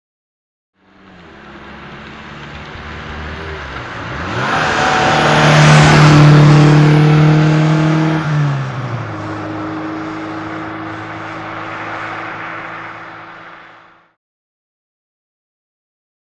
Automobile; By; Audi 80l Approach Up Gears, Big Change Down Before Mic. And Pull Away.